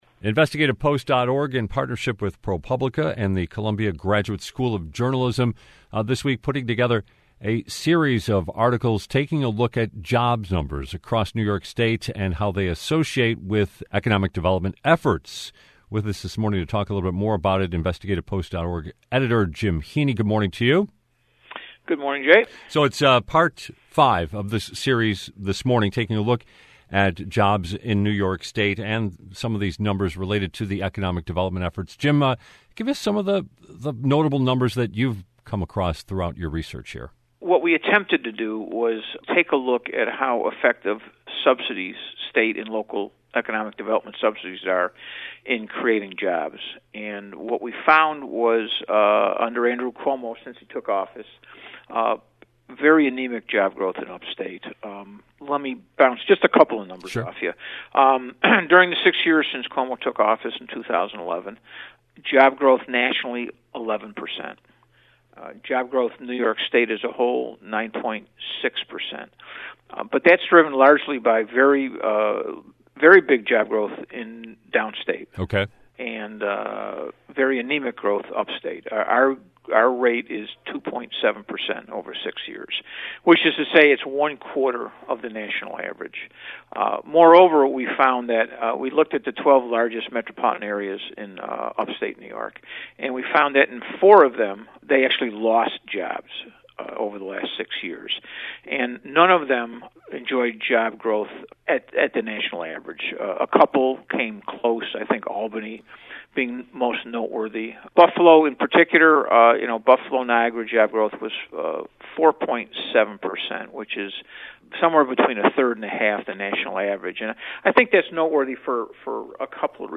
Posted below is the unabridged version of the interview. An edited version aired on WBFO.